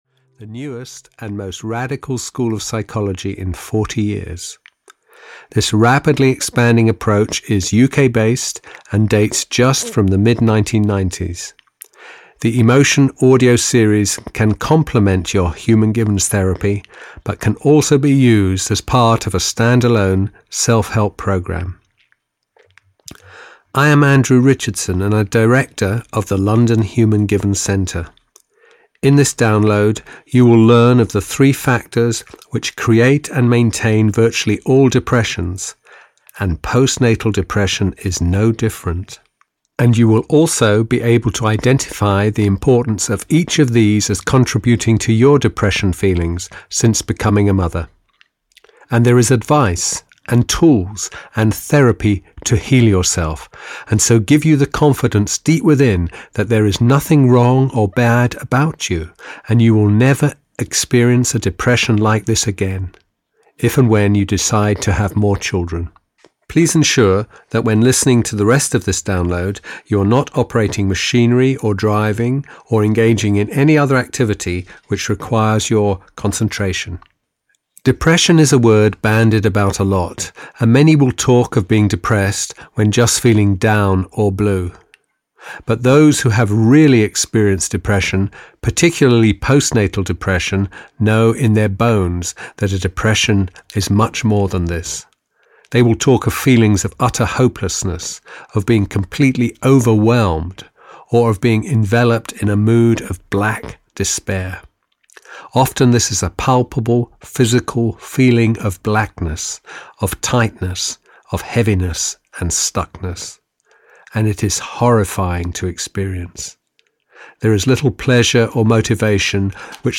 Overcoming Postnatal Depression (EN) audiokniha
Ukázka z knihy